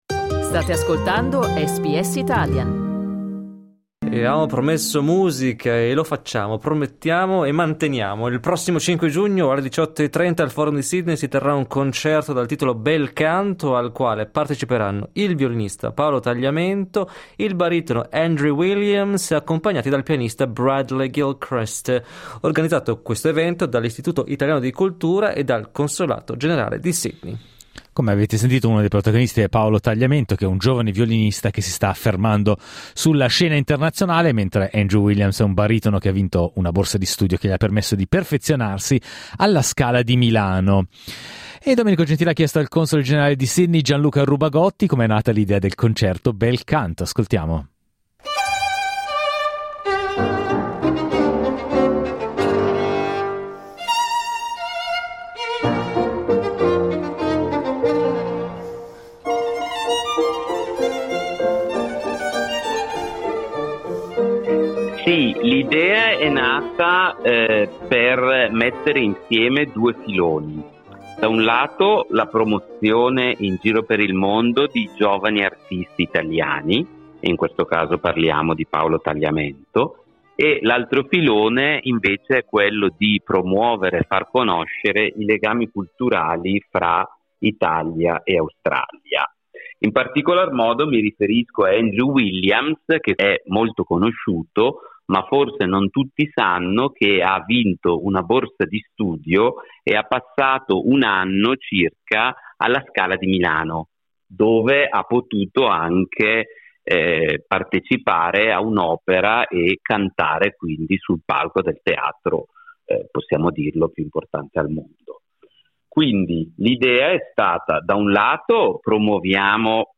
Il console genarale di Sydney Gianluca Rubagotti parla del concerto dal titolo "Bel canto" che si terrà il 5 giugno al Forum di Leichhardt.